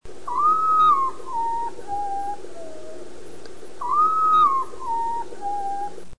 urutau da Praça da Matriz, ainda bem que ele não mostrou sua voz naquele dia. Todos sons do centro da cidade de Ubatuba.